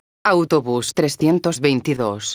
megafonias exteriores
autobus_322.wav